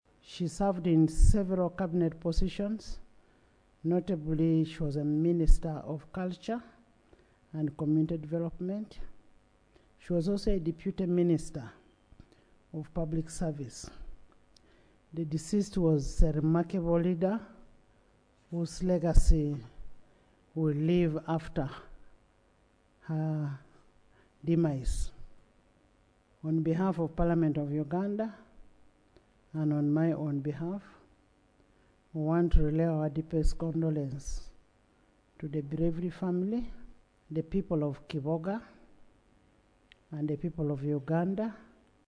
Speaker of Parliament Anita Among, while opening the plenary sitting on Tuesday, 5 August 2025 described Kalema as “a remarkable leader whose legacy will live after her demise.”
AUDIO: Speaker Among
Among on Rhoda Kalema.mp3